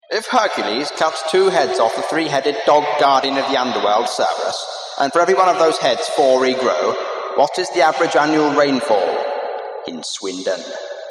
Voice of the Wall Demon